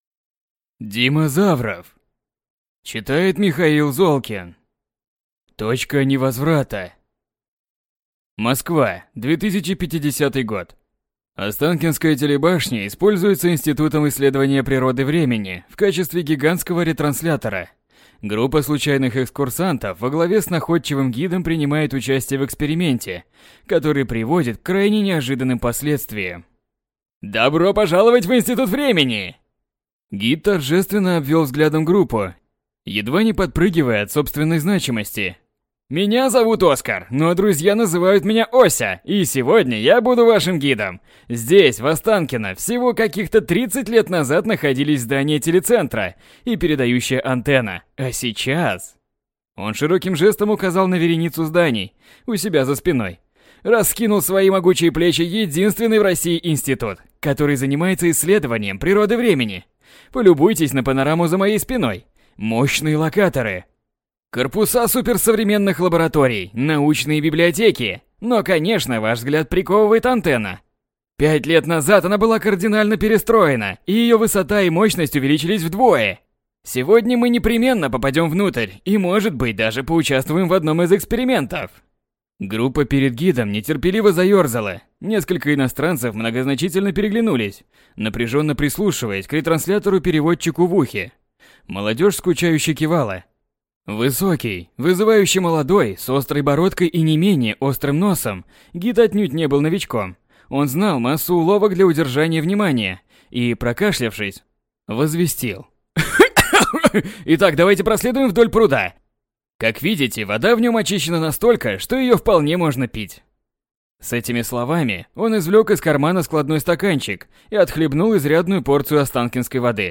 Аудиокнига Точка невозврата | Библиотека аудиокниг
Прослушать и бесплатно скачать фрагмент аудиокниги